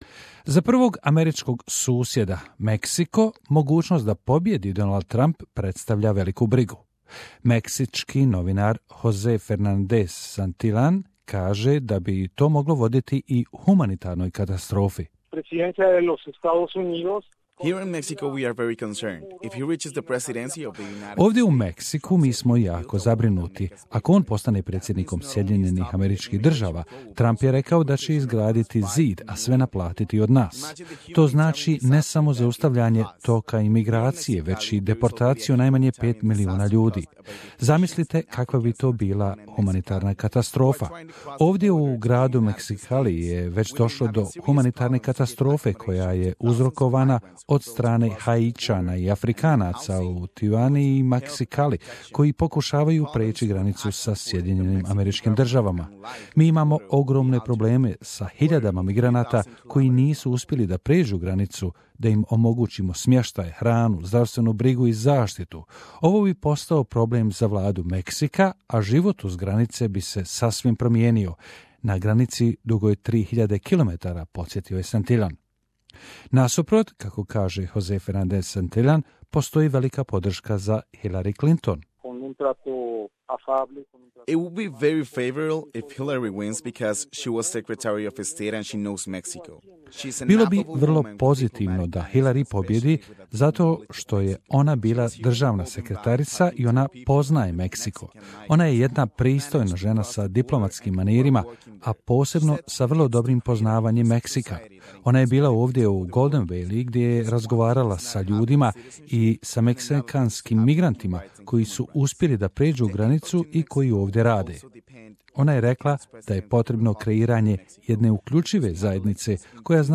Ahead of next week's United States presidential election, SBS Radio has asked journalists across a range of countries what a Donald Trump or Hillary Clinton victory would mean for their regions. Interviews by SBS Radio's language programs.